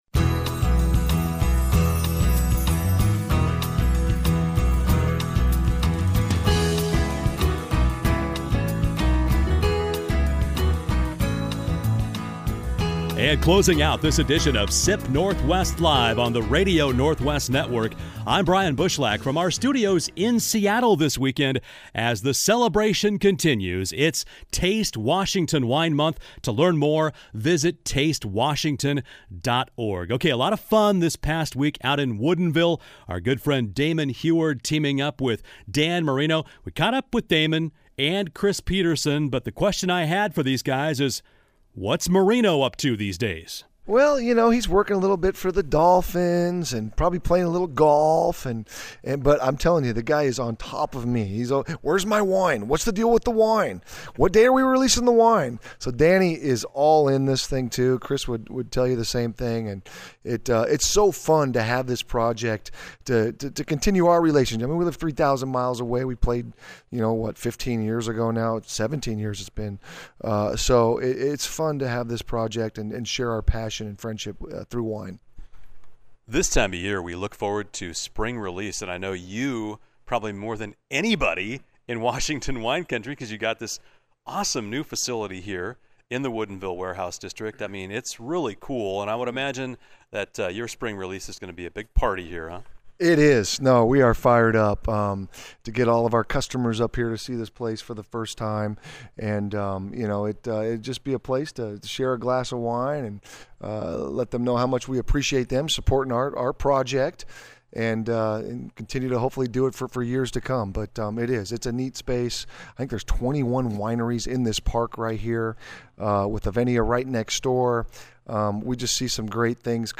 SIP NORTHWEST LIVE: RADIO INTERVIEWS